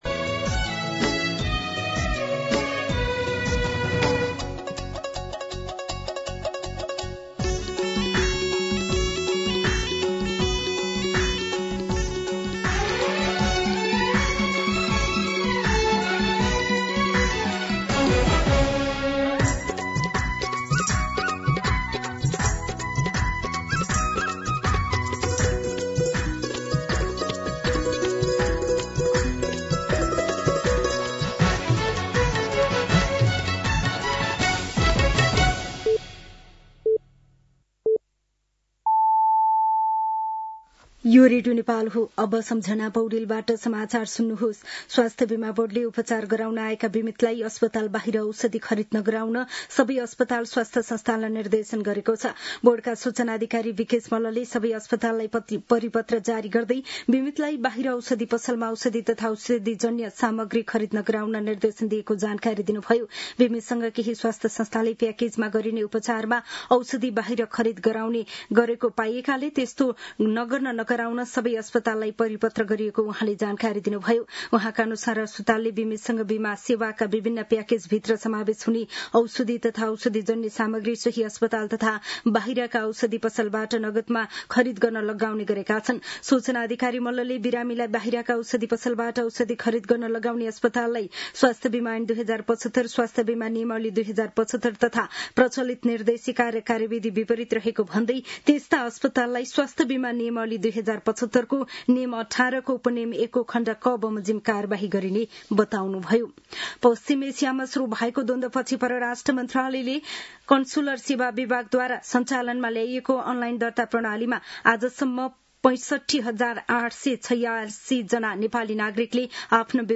दिउँसो ४ बजेको नेपाली समाचार : २८ फागुन , २०८२